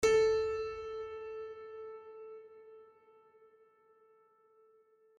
sounds / LoudAndProudPiano / a3.mp3